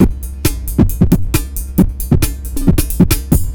ELECTRO 15-R.wav